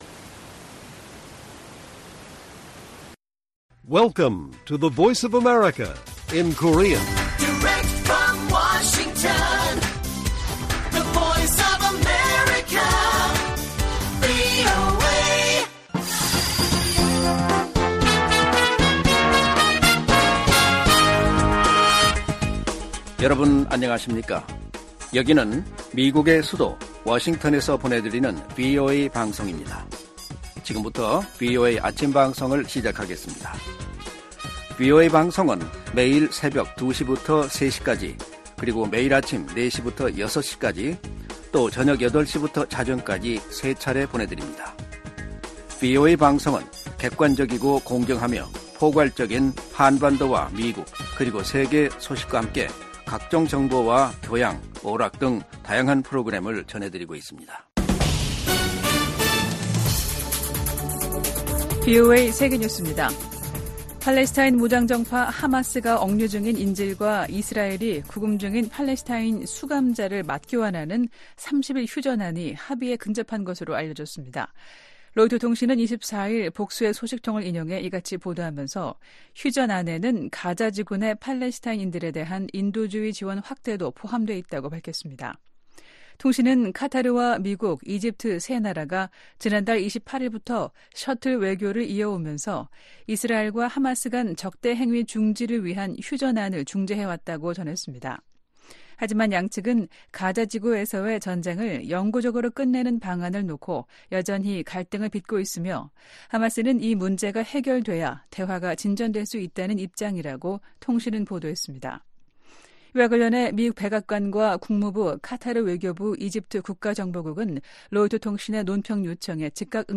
세계 뉴스와 함께 미국의 모든 것을 소개하는 '생방송 여기는 워싱턴입니다', 2024년 1월 25일 아침 방송입니다. '지구촌 오늘'에서는 튀르키예 의회가 스웨덴의 북대서양조약기구(NATO∙나토) 가입 비준안을 승인한 소식 전해드리고, '아메리카 나우'에서는 도널드 트럼프 전 대통령이 뉴햄프셔 프라이머리에서 승리한 이야기 살펴보겠습니다.